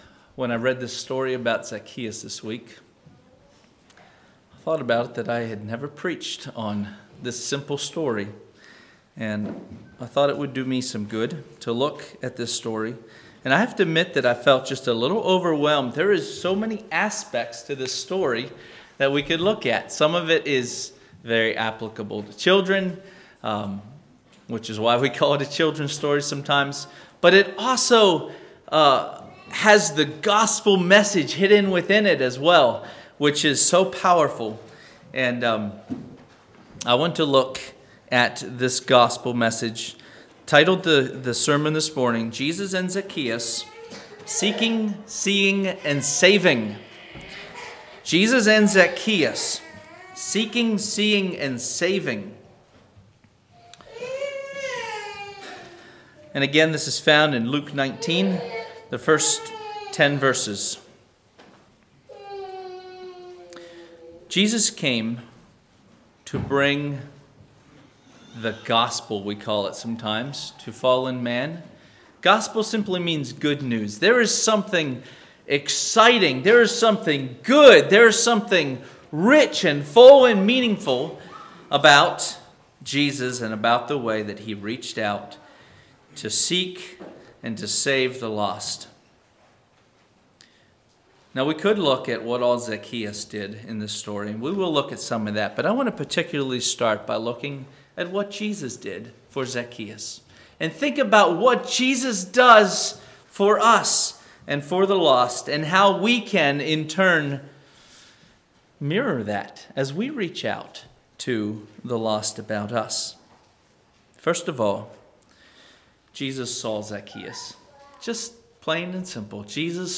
Sermons
Kirkwood | All Day Meetings 2024